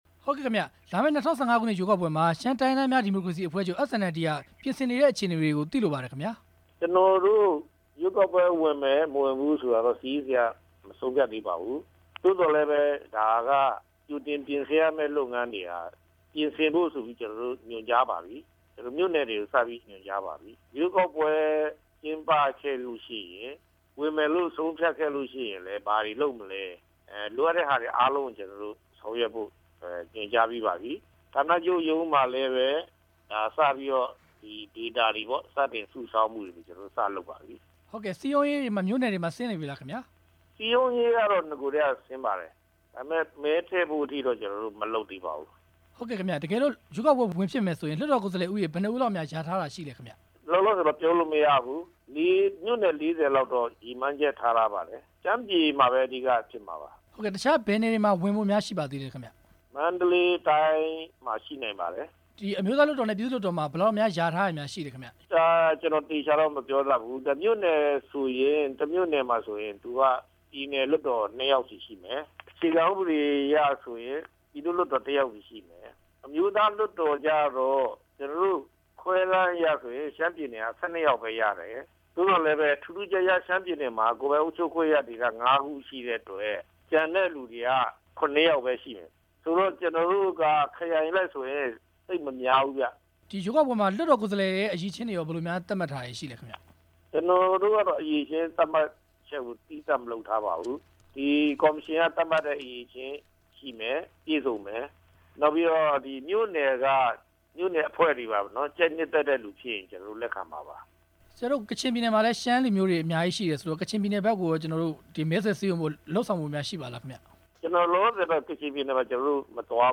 လာမယ့်ရွေးကောက်ပွဲမှာ ကျားခေါင်းပါတီ ပါဝင်ယှဉ်ပြိုင်ဖို့ ပြင်ဆင်နေမှုအပေါ် မေးမြန်းချက်